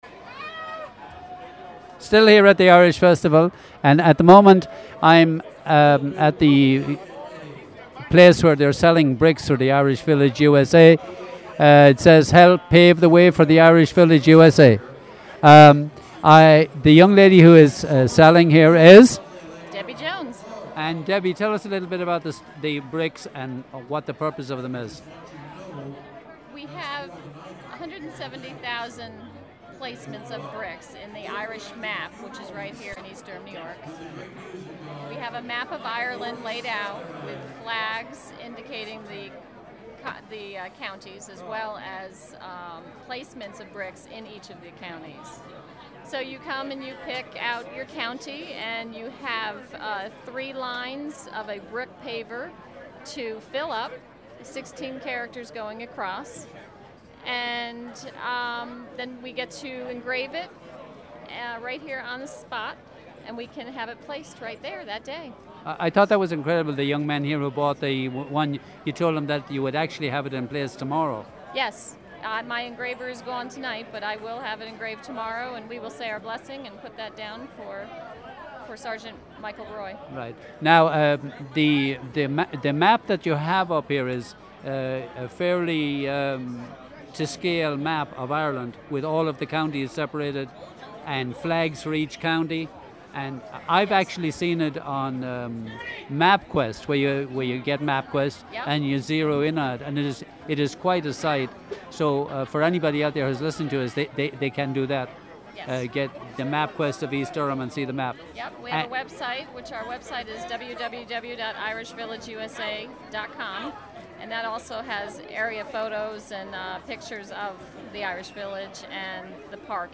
East Durham Irish Festival: May 29, 2010 - May 30, 2010